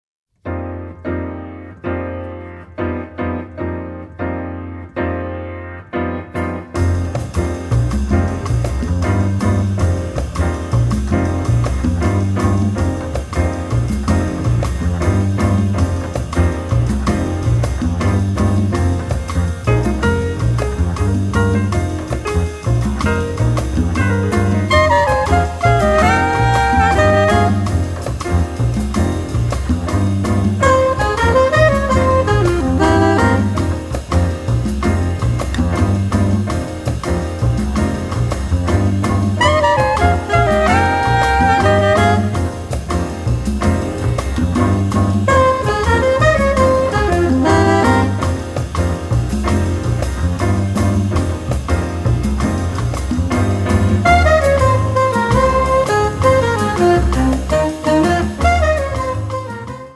sax tenore e soprano
pianoforte
contrabbasso
batteria
congas